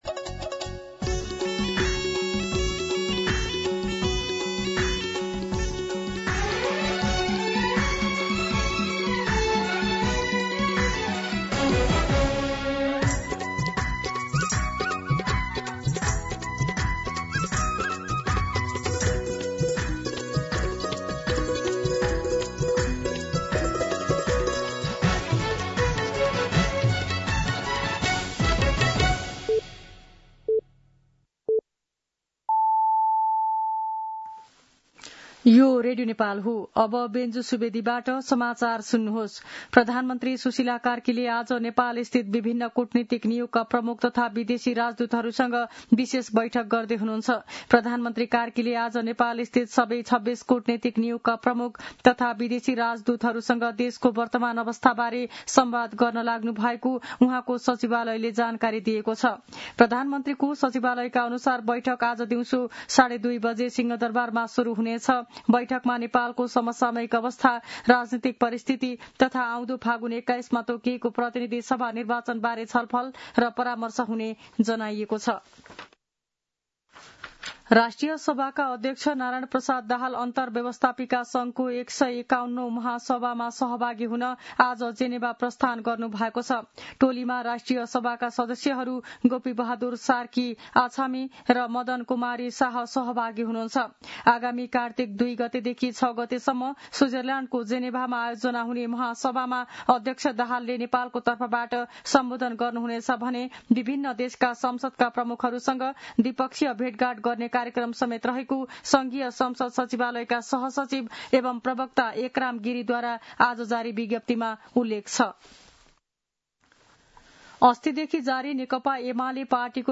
दिउँसो १ बजेको नेपाली समाचार : ३१ असोज , २०८२
1-pm-Nepali-News-8.mp3